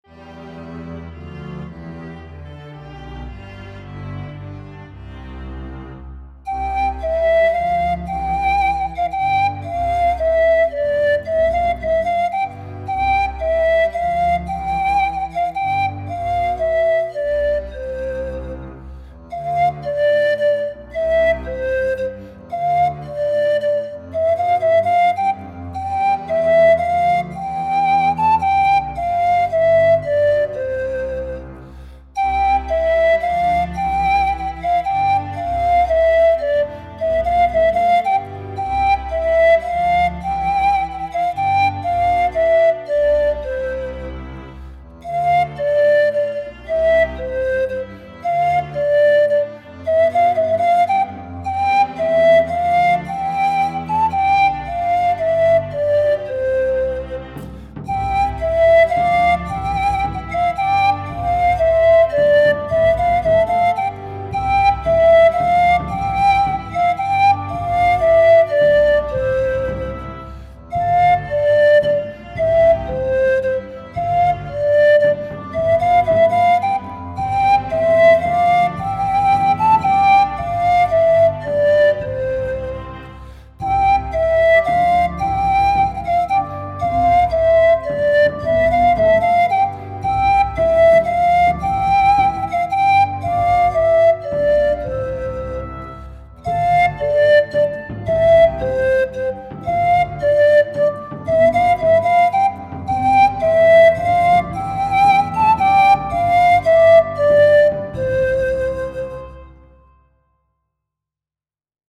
Noël provençal de Micoulau Saboly
En do majeur, avec un accompagnement de cordes et cor anglais.
Ceux-là liront l’article Savoir écouter la musique et noteront que pour ce karaoké les instruments ont été clairement « spacialisés » avec notamment la contrebasse à gauche et le violoncelle à droite.
Piesque l’orgueil (sans flûte)